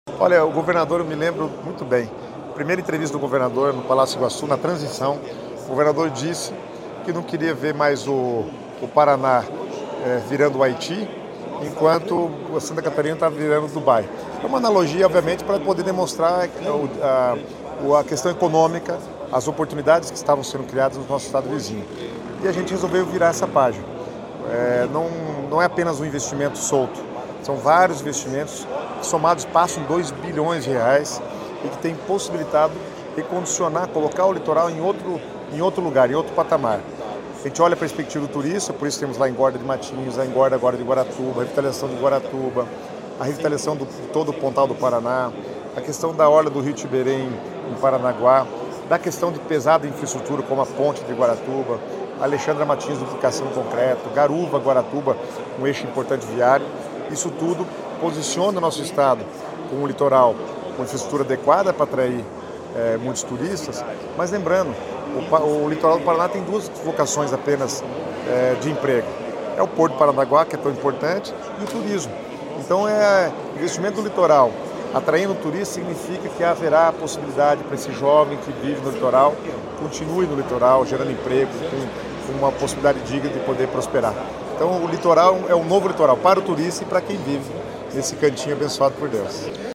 Sonora do secretário das Cidades, Guto Silva, sobre os investimentos no litoral paranaense